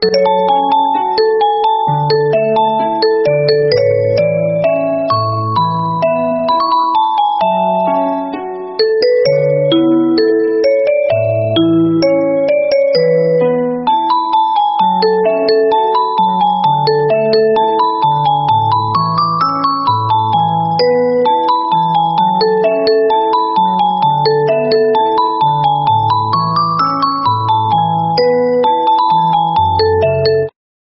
Nada Dering Panggilan HP Android
Kategori: Nada dering
Keterangan: Unduh nada dering panggilan HP Android mp3 versi kalem, viral TikTok, cocok untuk WA dan semua HP.
nada-dering-panggilan-hp-android-id-www_tiengdong_com.mp3